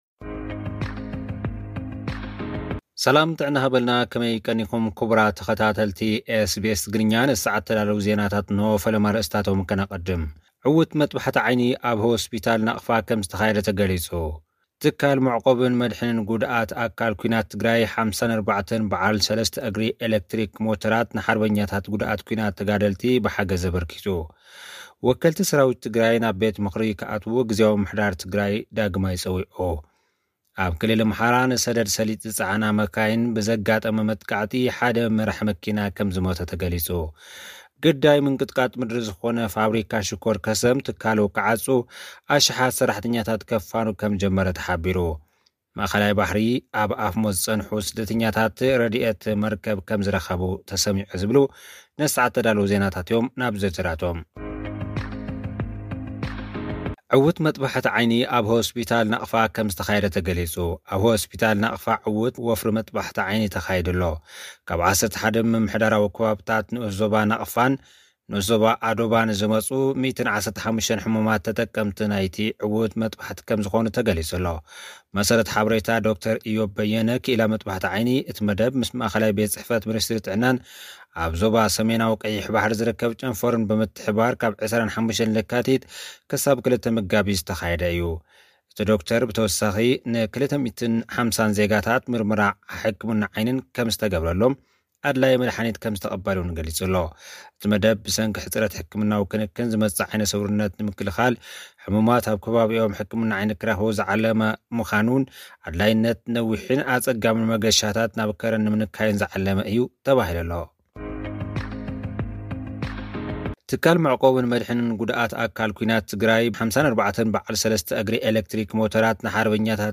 ኣብ ማእከላይ ባሕሪ፡ ኣብ ኣፍ ሞት ዝጸንሑ ስደተኛታት ረዳኢት መርከብ ረኺቦም። (ጸብጻብ)